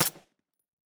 sounds / weapons / _bolt / 762_4.ogg